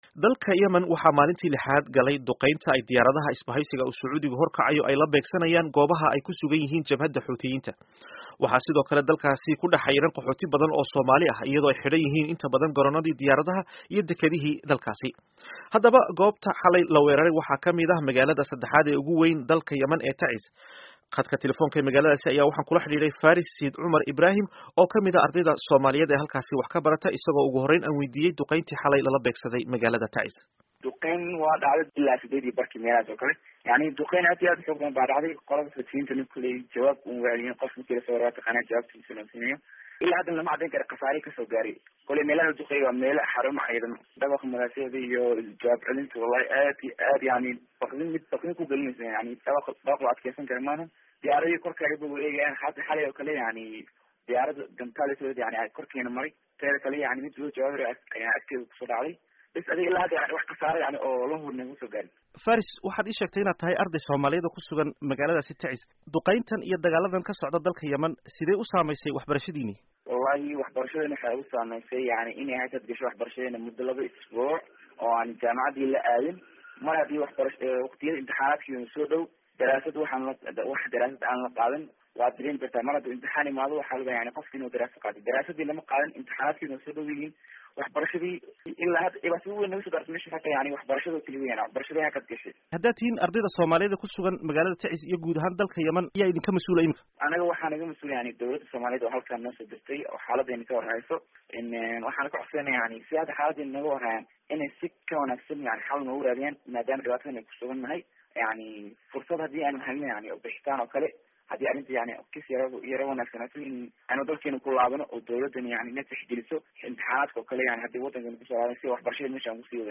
Dhegayso: Warbixinno ku saabsan Qaxootiga Dagaalka Yemen